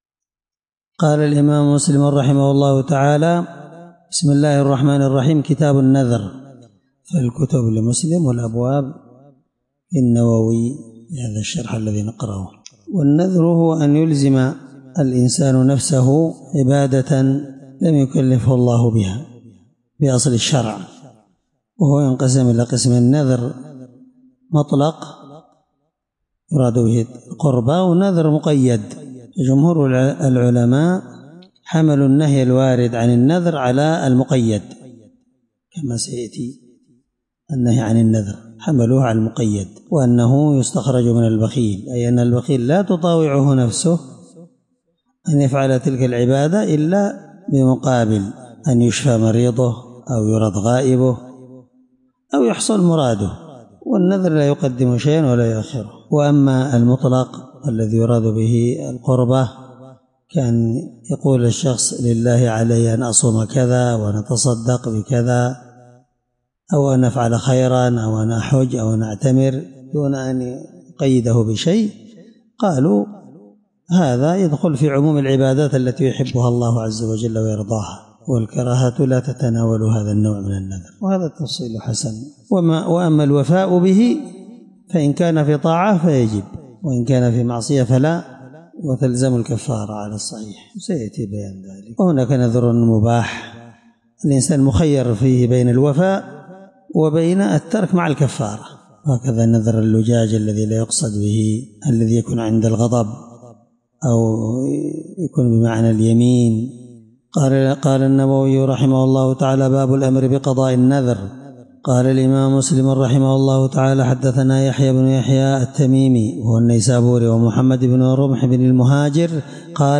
الدرس1من شرح كتاب النذر حديث رقم(1638) من صحيح مسلم